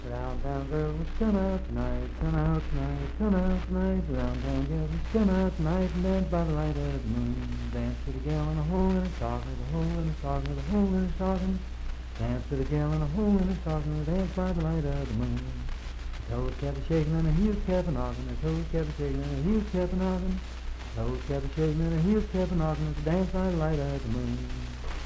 Unaccompanied vocal music
Dance, Game, and Party Songs
Voice (sung)
Marlinton (W. Va.), Pocahontas County (W. Va.)